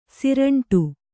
♪ siraṇṭu